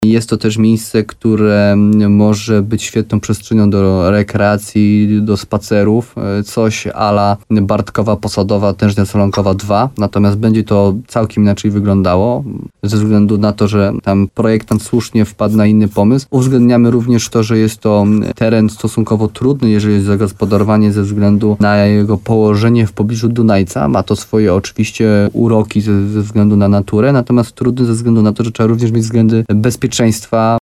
Jak powiedział wójt Jarosław Baziak, koncepcja musi być dokładnie dopracowana ze względu na niełatwą specyfikę terenu.